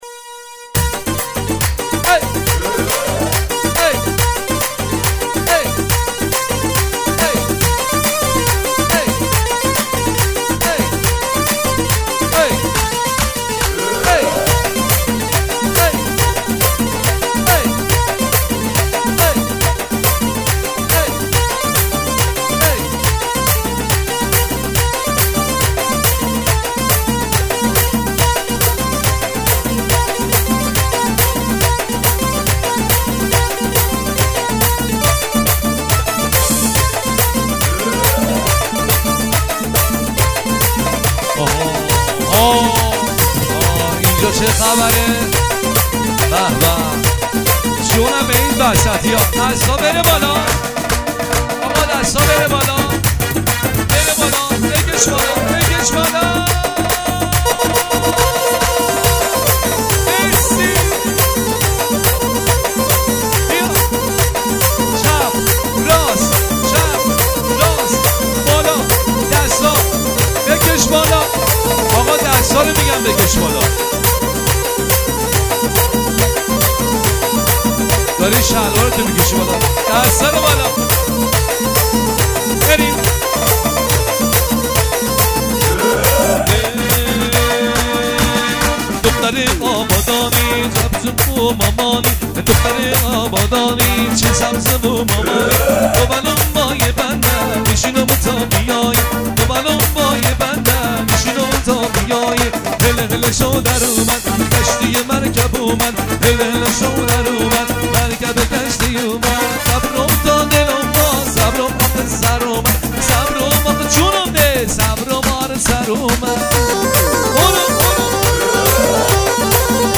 آهنگ ارکستری
آهنگ ارکستی شاد